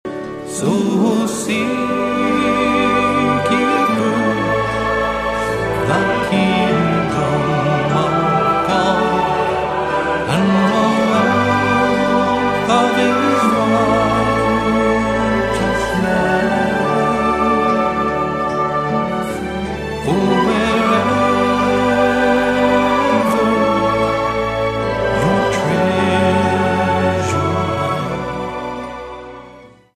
STYLE: Ambient/Meditational
guitar
expressive and distinctive vocals
haunting, wistful and well crafted acoustic music